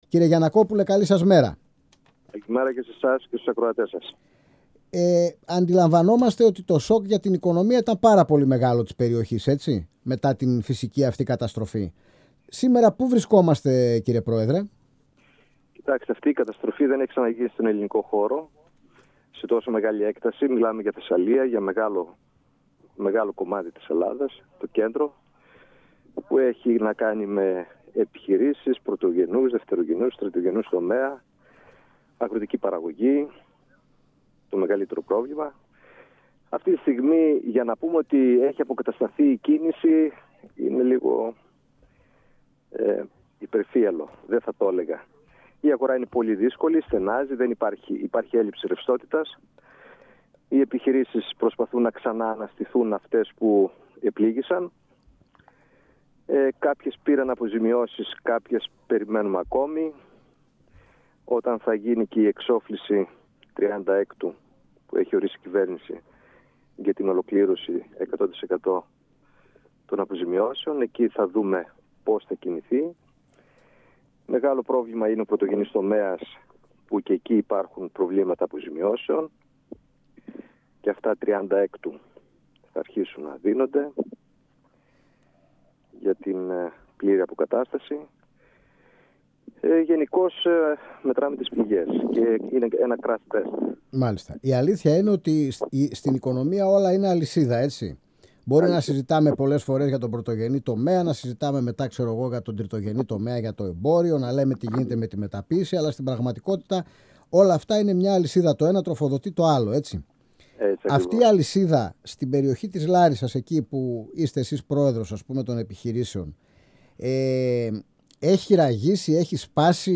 μιλώντας στην εκπομπή «Αίθουσα Σύνταξης» του 102FM της ΕΡΤ3.